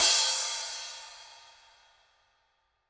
soft-hitfinish.ogg